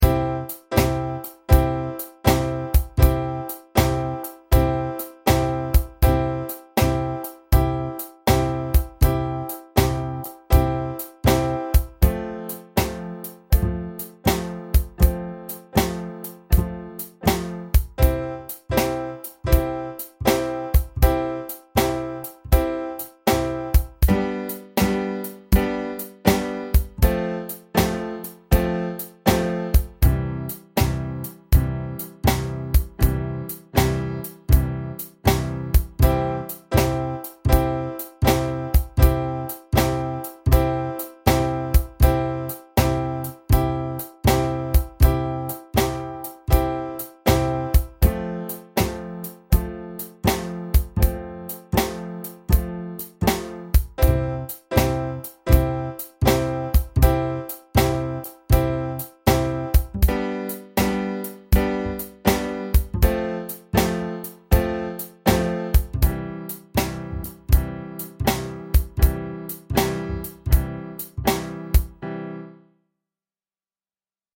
Blues track with drums